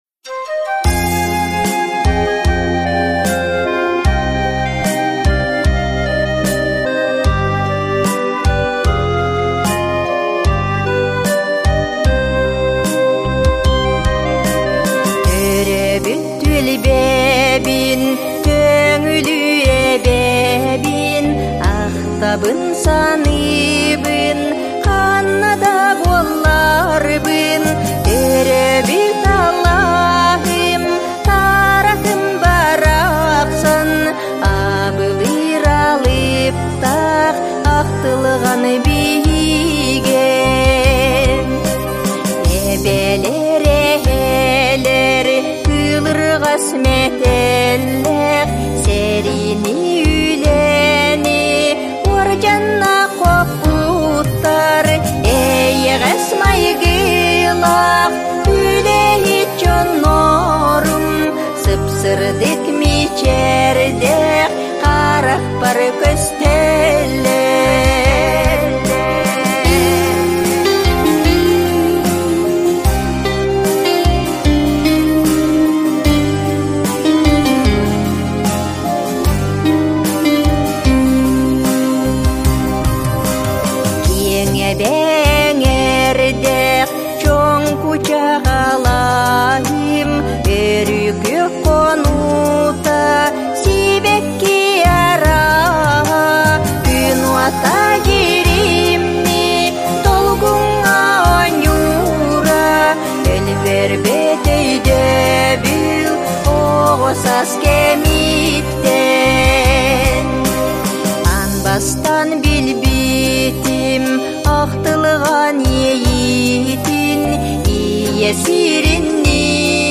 студиятыгар уhуллан